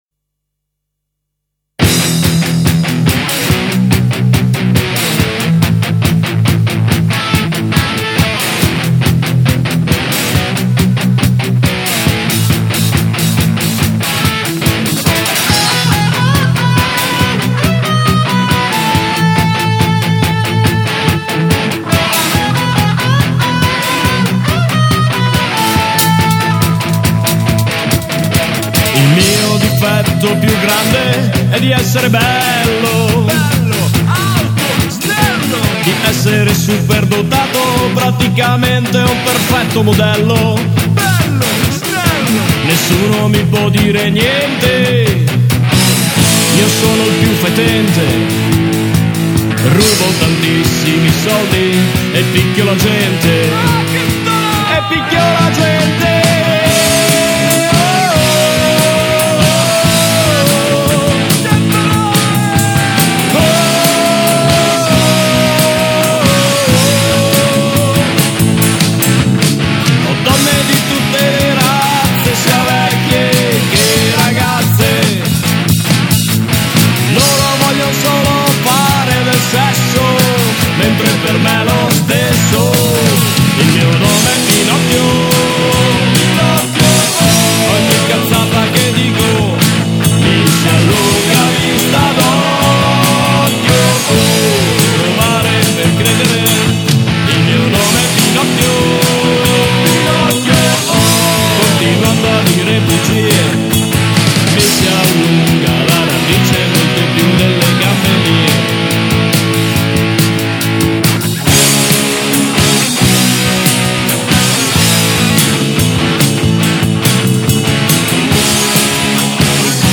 Musica Demenziale e altro...